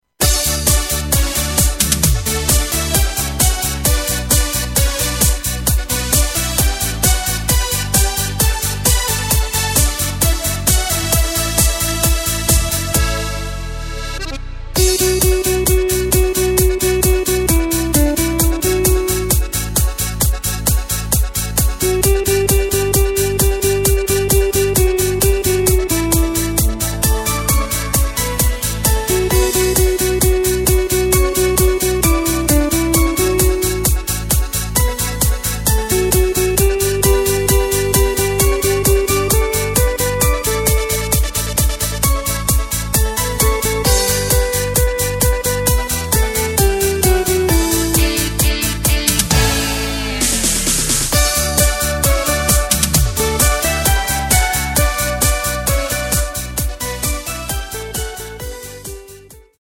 Takt:          4/4
Tempo:         132.00
Tonart:            D
Discofox aus dem Jahr 2014!